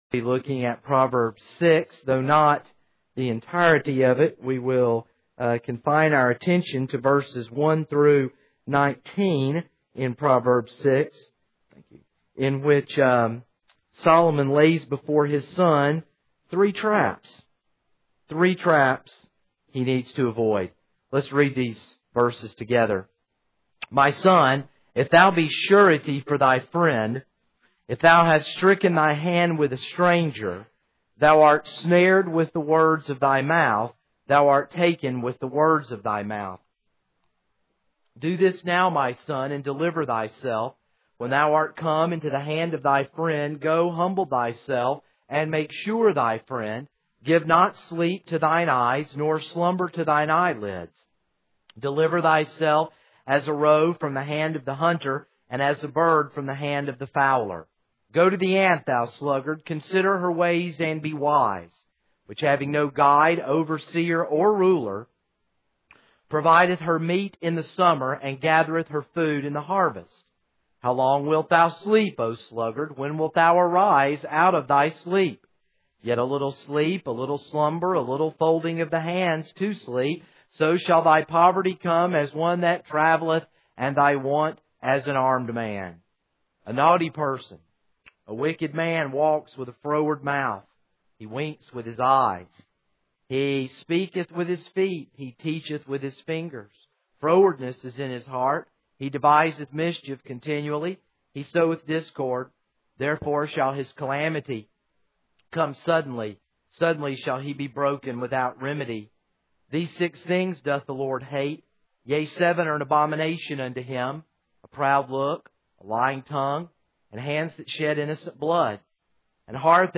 This is a sermon on Proverbs 6:1.